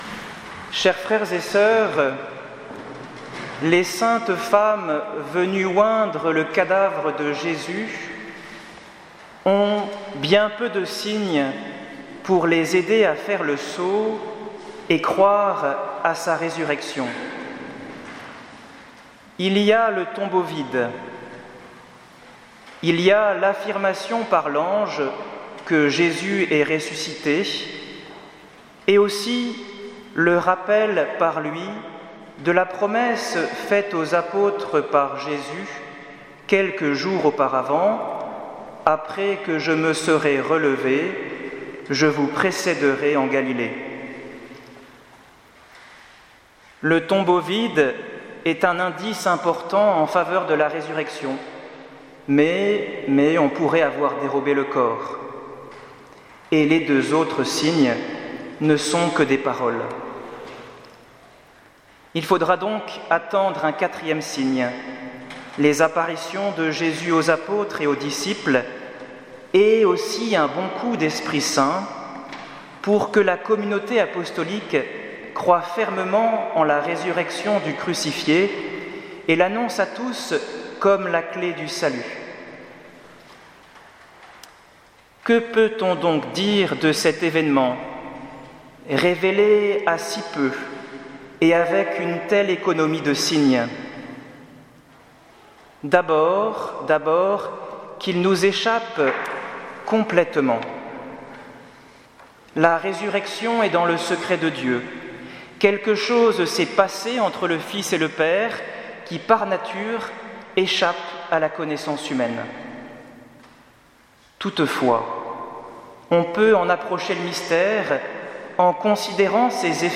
Homélie de la Veillée Pascale 2018